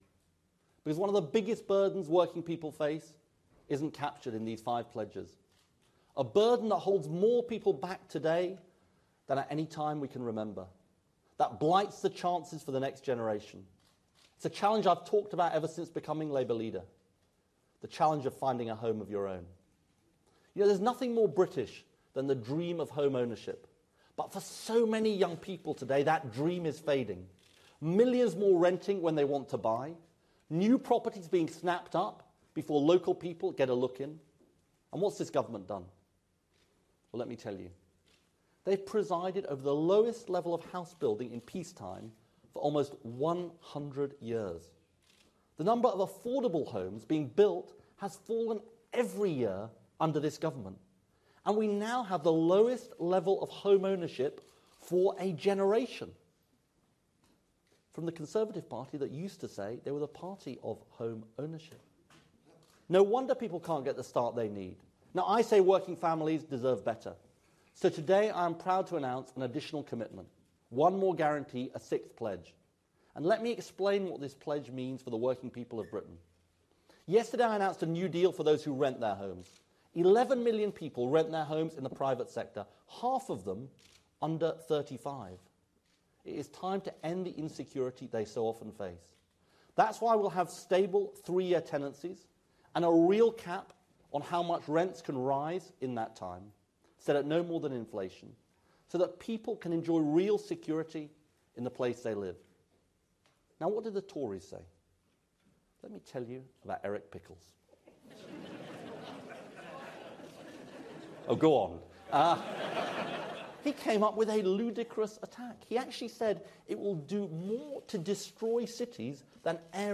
Speech on housing, 27 April 2015